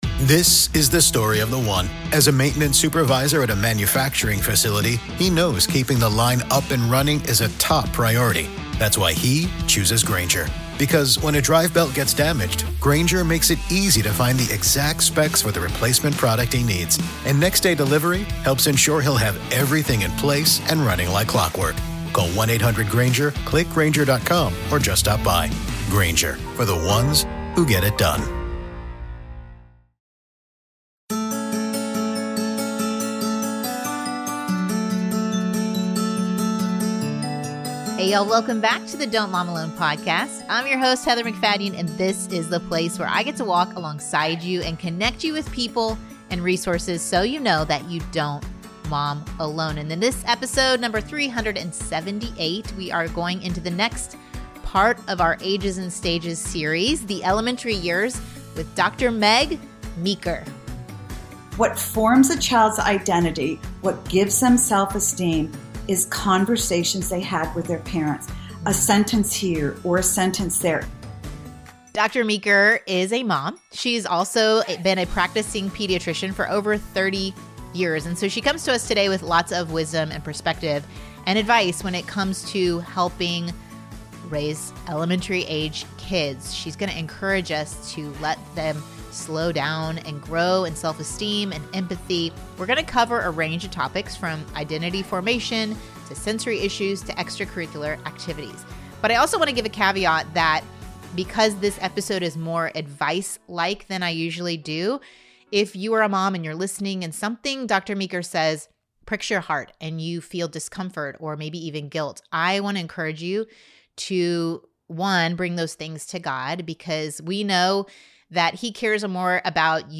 My guest this week is pediatrician, author, speaker, and mom